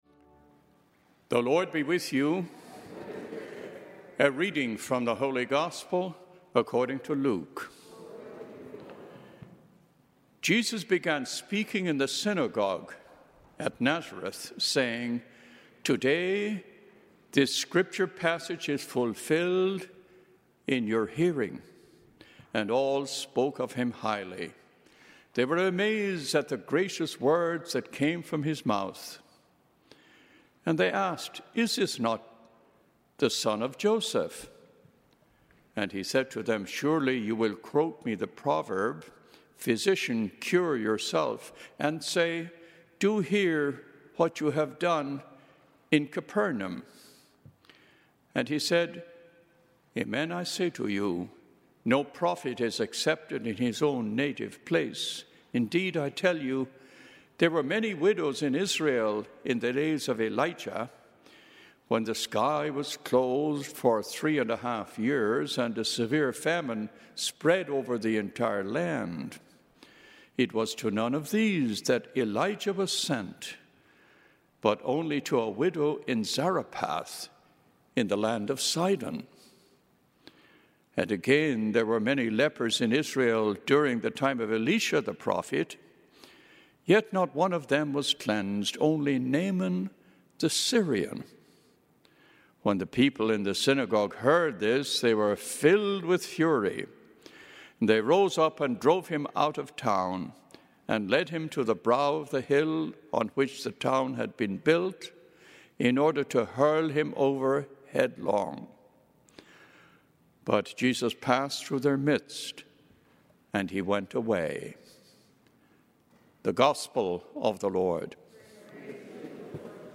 Gospel and Homily Podcasts
9:30 Mass